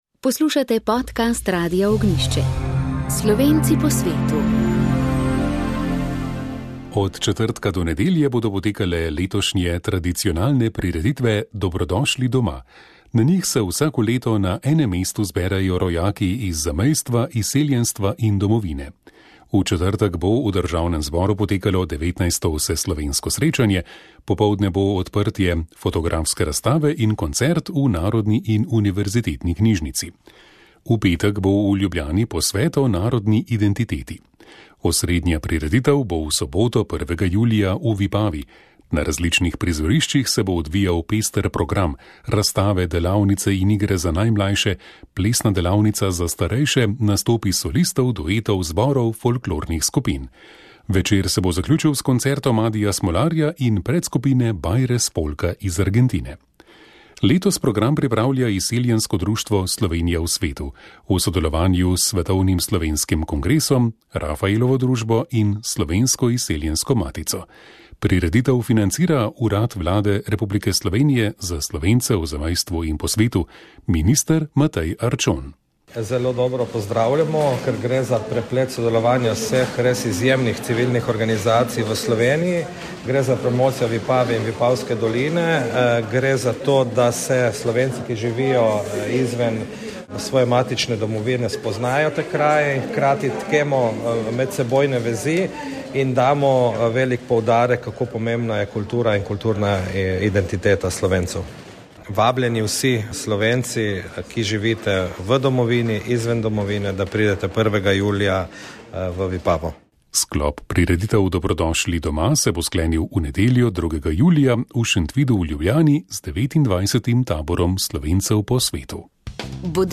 Izseljensko društvo Slovenija v svetu že več kot 30 let pomaga vsem, ki se obrnejo nanj za pomoč pri vračanju v Slovenijo in posreduje pri uradih in različnih postopkih. Tako je okroglo mizo letošnjega 30. Tabora Slovencev po svetu posvetilo prav vračanju.